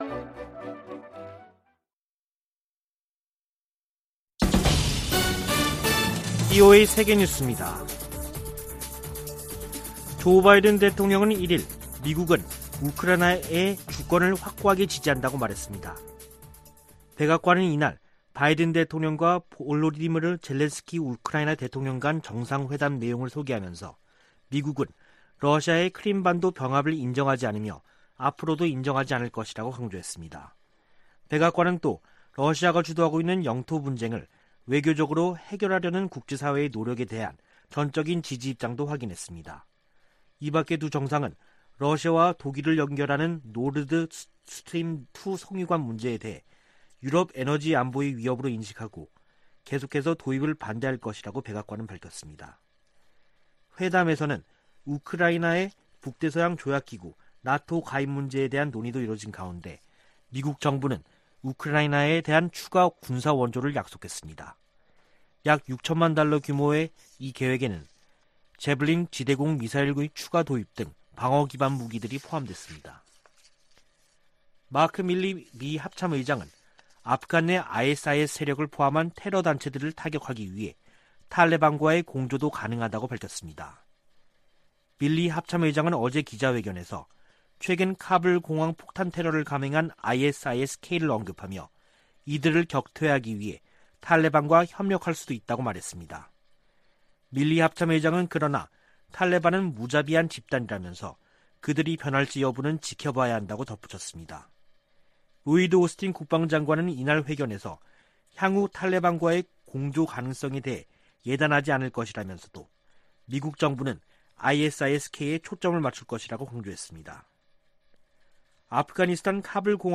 VOA 한국어 간판 뉴스 프로그램 '뉴스 투데이', 3부 방송입니다. 미 국무부가 미국인들의 북한 여행금지 조치를 1년 더 연장하기로 했습니다. 미국의 구호 단체들과 이산가족 단체들은 북한 여행금지 재연장에 실망을 표시했습니다. 미 공화당 의원들은 영변 핵 시설 재가동은 북한이 미국과 선의로 비핵화 협상을 할 의향이 없다는 증거라며, 북한에 최대 압박을 가해야 한다고 바이든 행정부에 촉구했습니다.